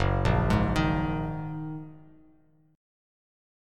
F#6add9 Chord